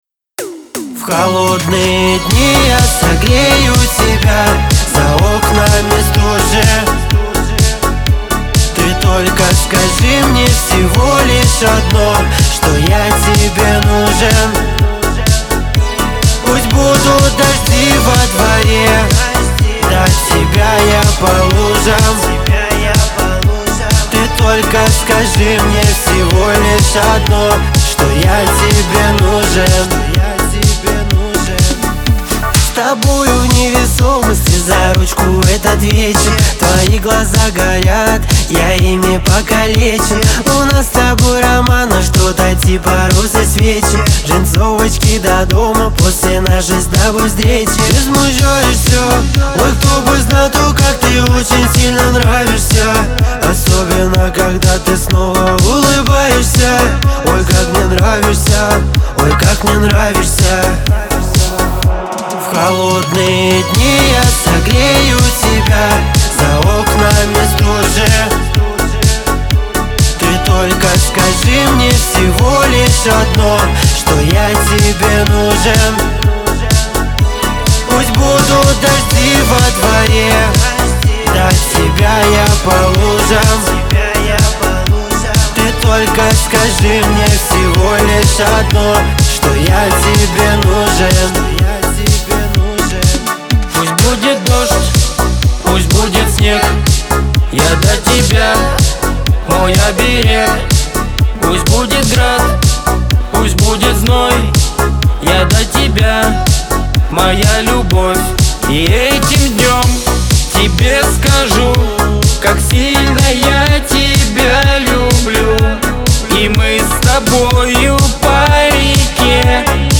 ХАУС-РЭП
грусть , дуэт